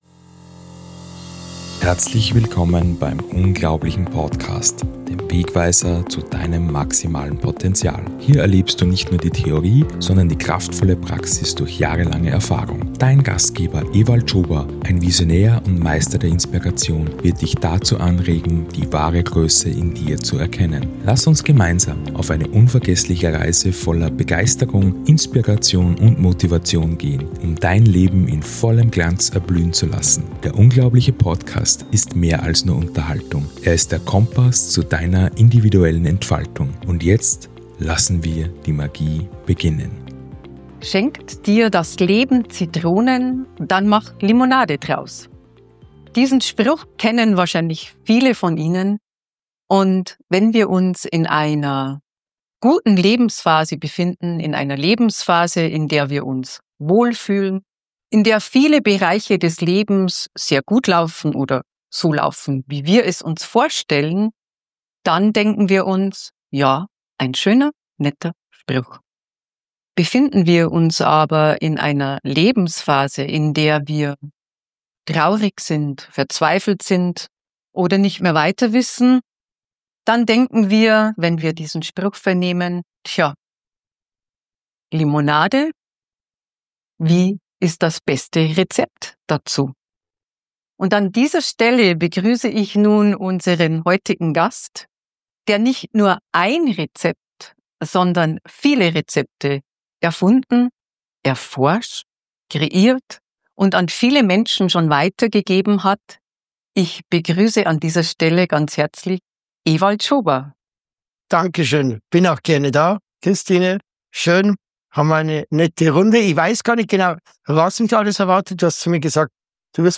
Diese Folge ist mehr als ein Interview.